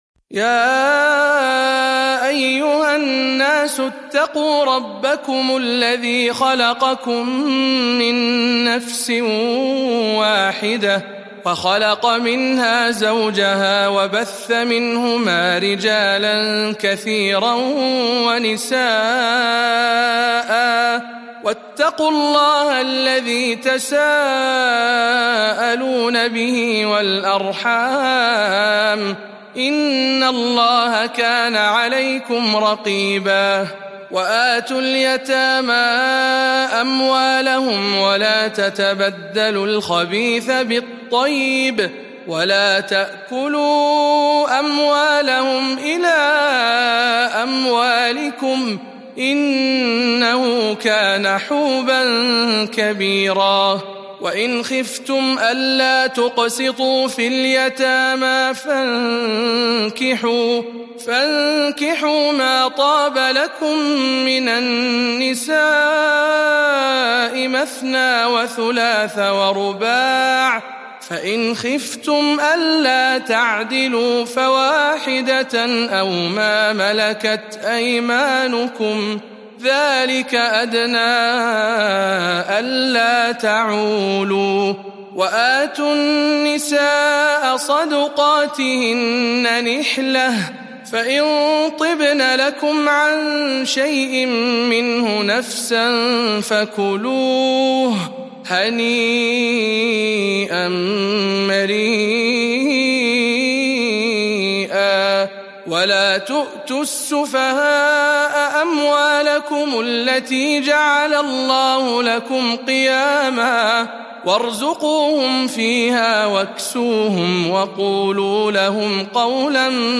سورة النساء برواية شعبة عن عاصم